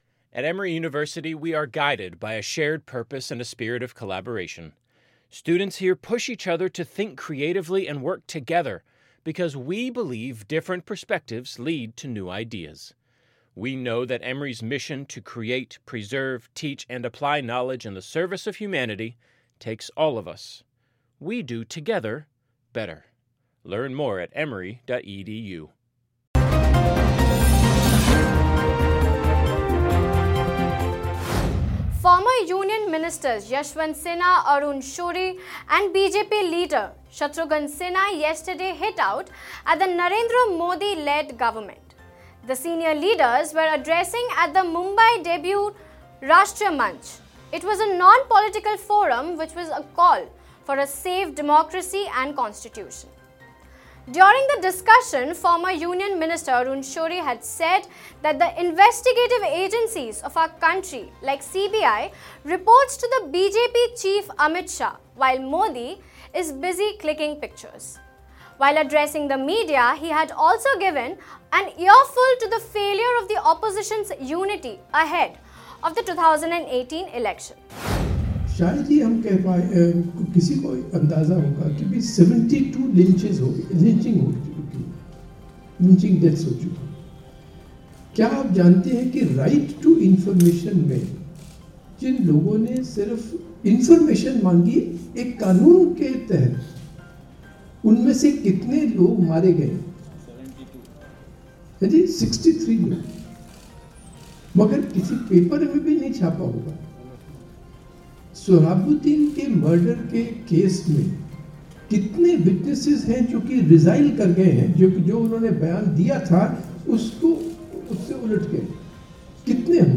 News Report / There is no fear in the media, but there is greed: Arun Shourie